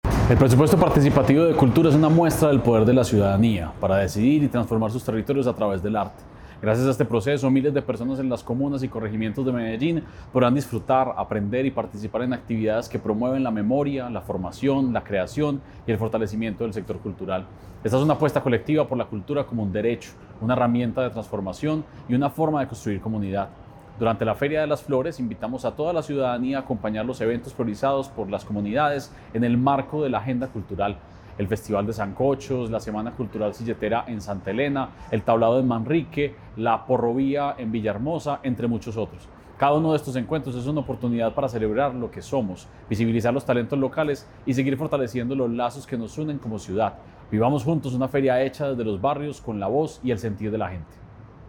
Declaraciones del secretario de Cultura Ciudadana, Santiago Silva Jaramillo
Declaraciones-del-secretario-de-Cultura-Ciudadana-Santiago-Silva-Jaramillo-1.mp3